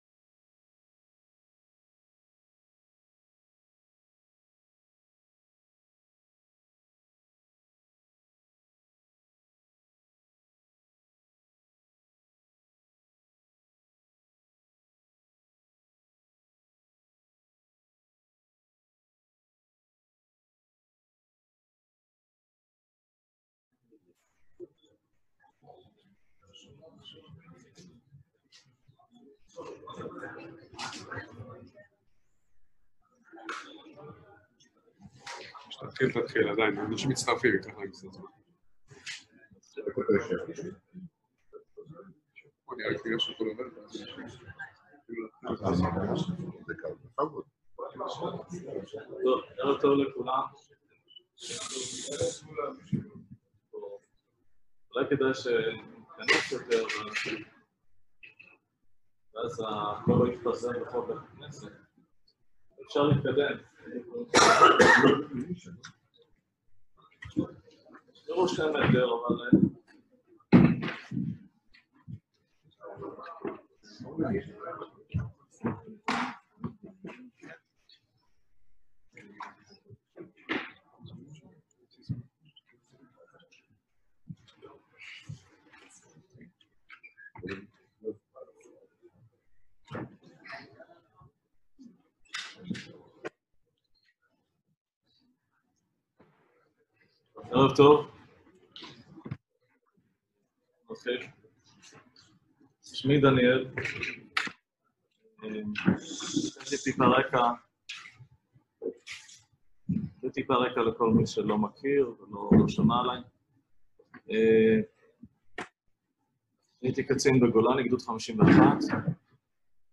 הרצאה ארוכה